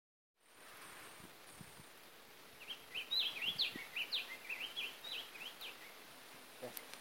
Frutero Azul (Stephanophorus diadematus)
Nombre en inglés: Diademed Tanager
Fase de la vida: Adulto
Localidad o área protegida: Delta del Paraná
Condición: Silvestre
Certeza: Vocalización Grabada